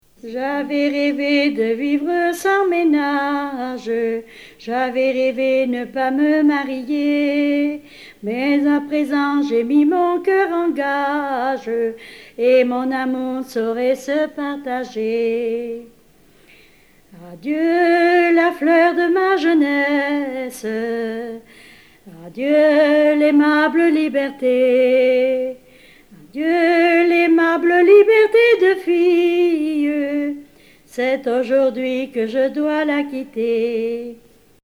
Enregistré sur disque
Original (extrait) :